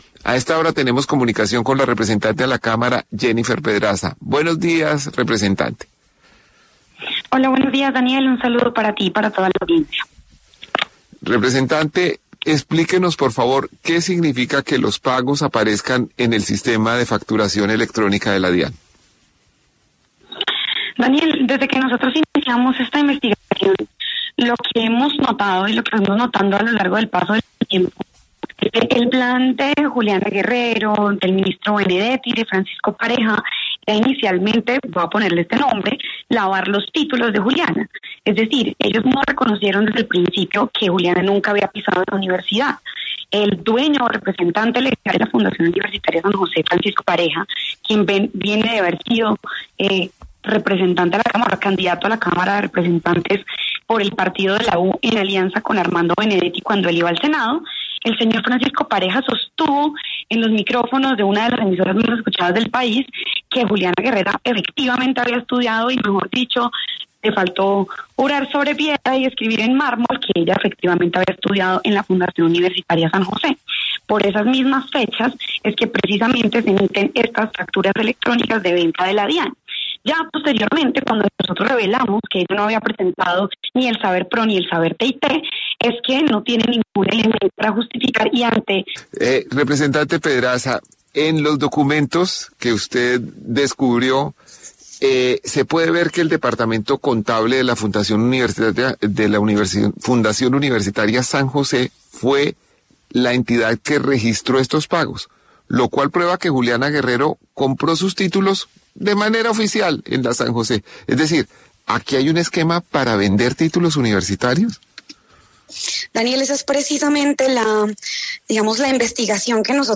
La representante Jennifer Pedraza pasó por los micrófonos de El Reporte Coronell de 6AM W para hablar acerca de la denuncia que evidenciaría la compra del título profesional por parte de Juliana Guerrero a la Fundación San José, diploma que le era requerido para ocupar el cargo de viceministra de las Juventudes en el Ministerio de la Igualdad.